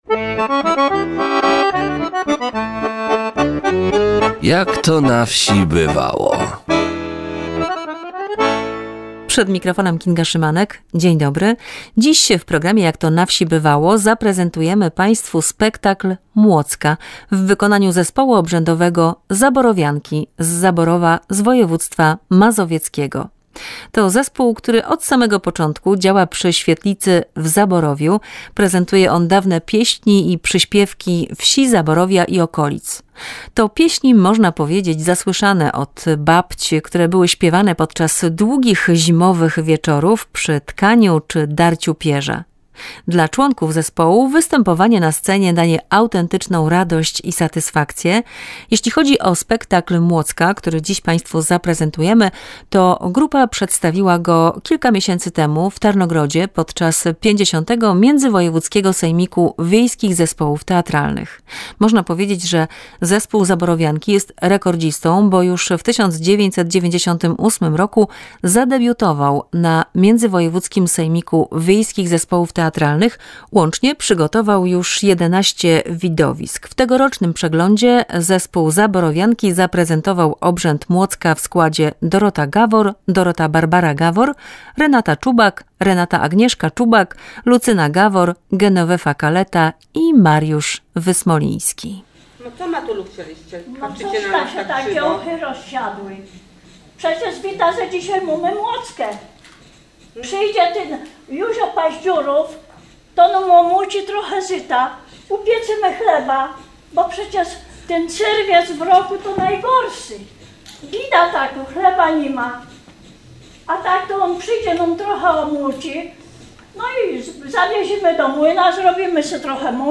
Widowisko „Młocka” w wykonaniu zespołu Zaborowianki z Zaborowa.